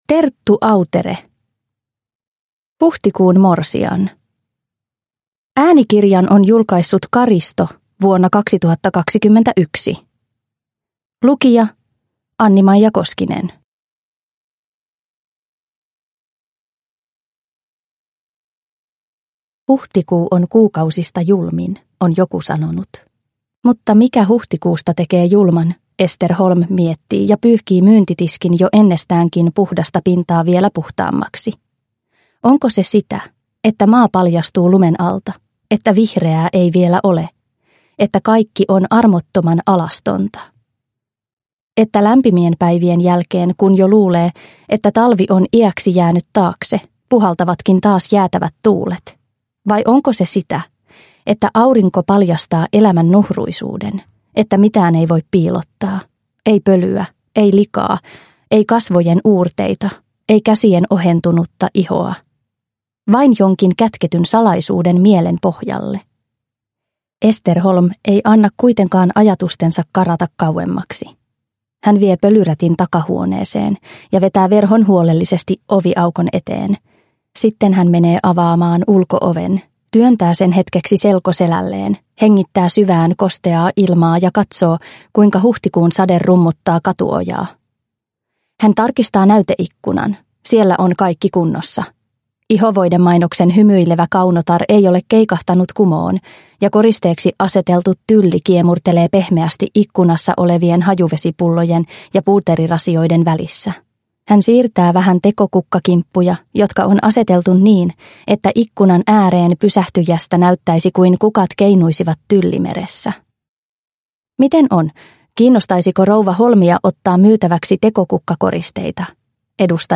Huhtikuun morsian – Ljudbok – Laddas ner